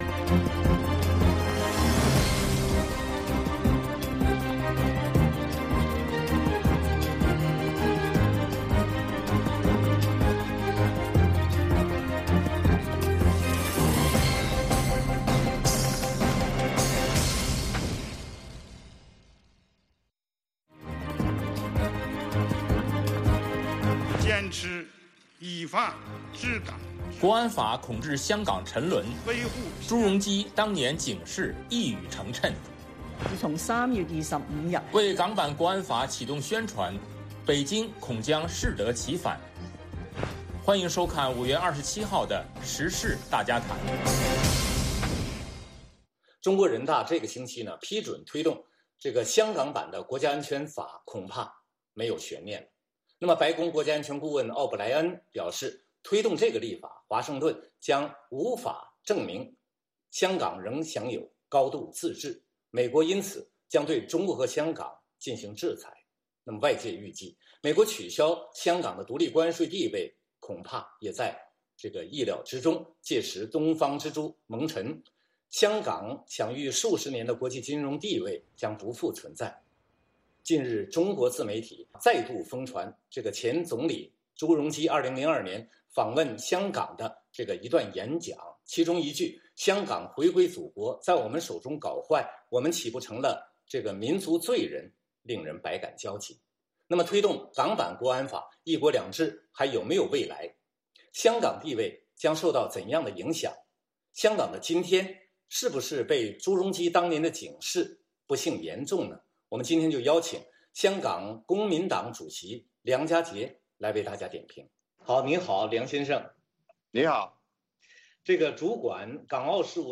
时事大家谈(2020年5月27日)：1)国安法恐致香港沉沦，朱镕基当年警示一语成谶？2)为港版国安法启动宣传 北京恐将适得其反？嘉宾：香港公民党主席梁家杰